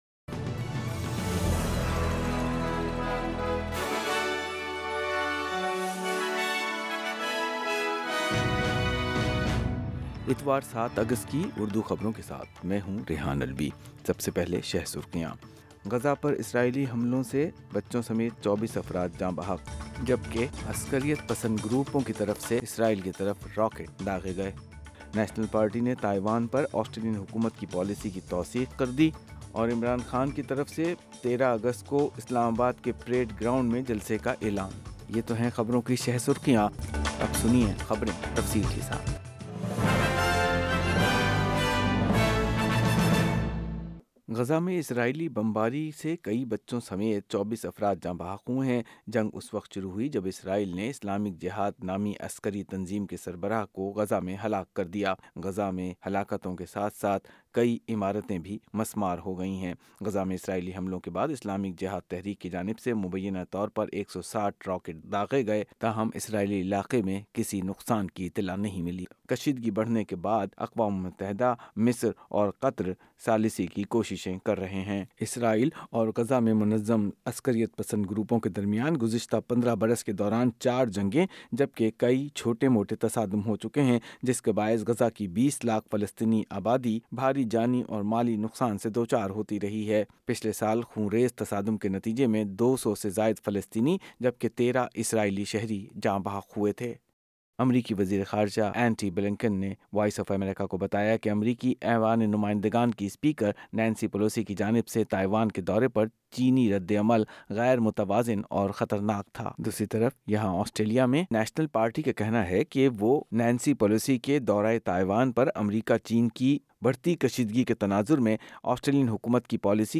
Urdu News Wed 3 Aug 2022